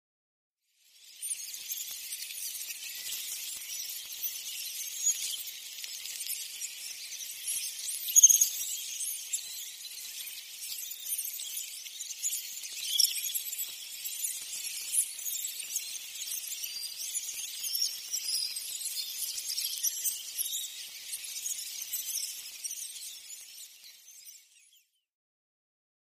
Rats Ambience 2 - Many Rats Sewage, Twittering, Fast Moving